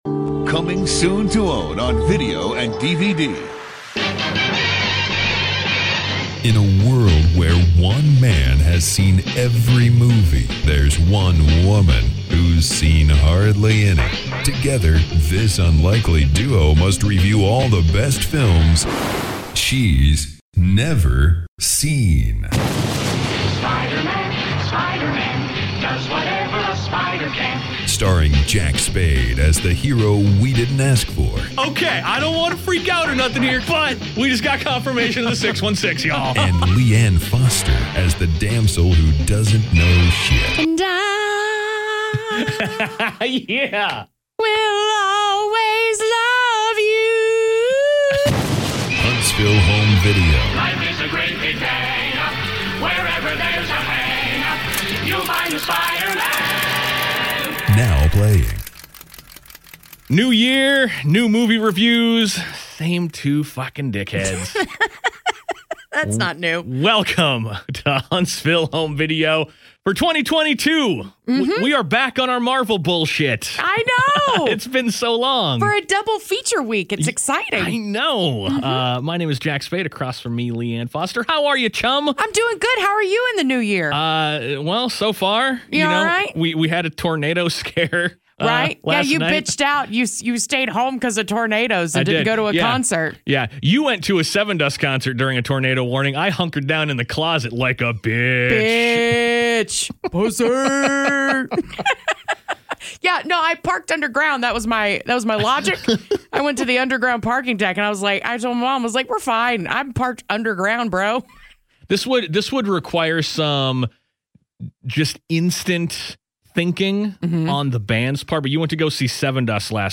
Tune in to the first of two Spider-Man movies this week, reviewed by the best damn duo on the six one six, confirmed!